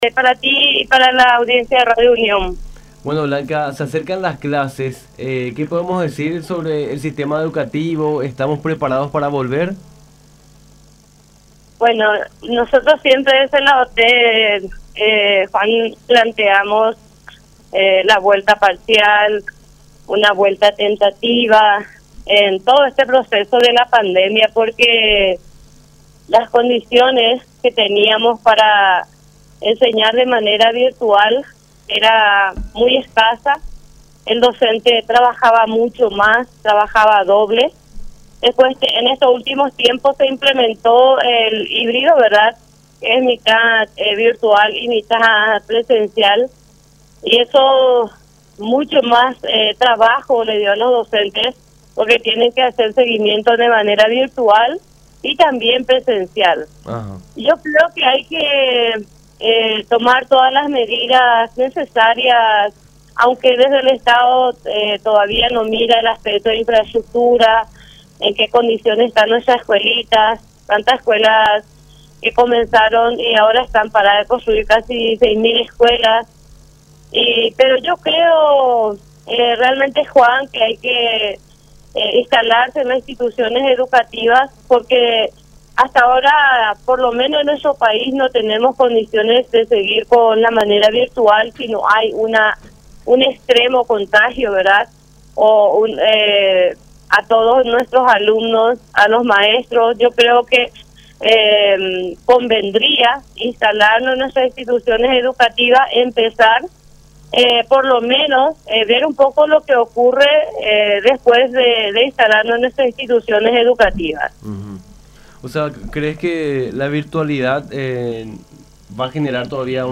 en diálogo con Nuestra Mañana por La Unión